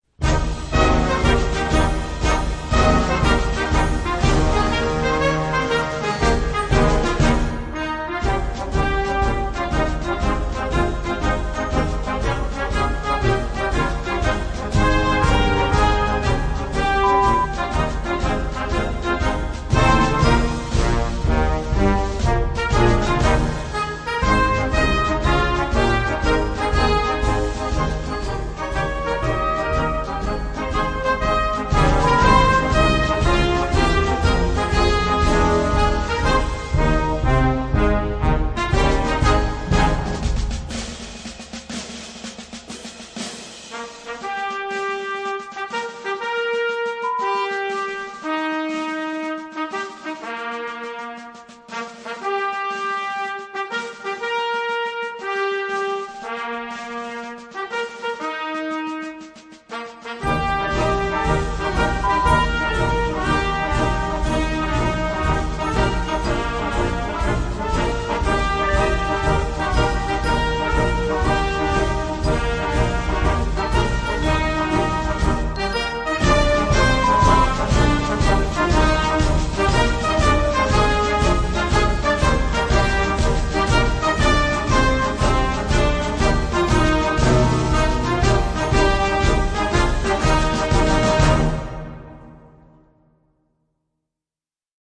Besetzung: Blasorchester
Einer der bekanntesten Märsche aller Zeiten.